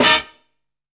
SWEET STAB.wav